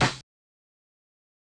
Bimmer snare.wav